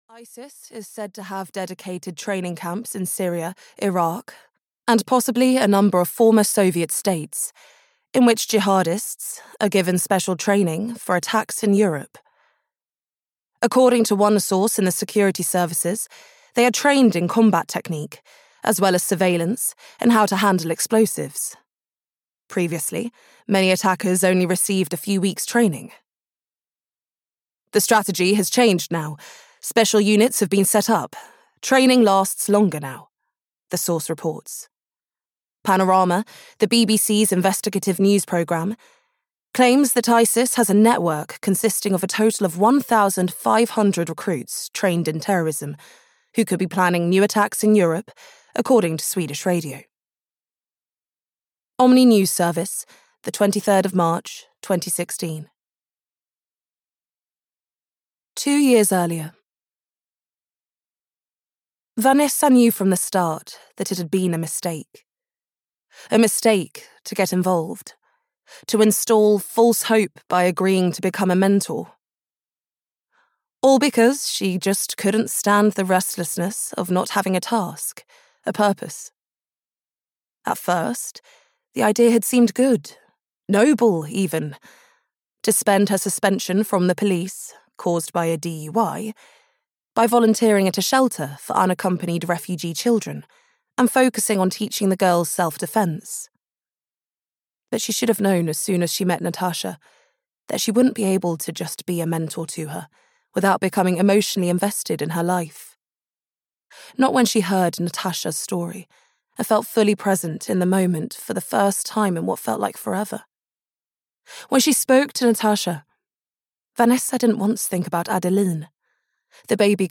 The Widows (EN) audiokniha
Ukázka z knihy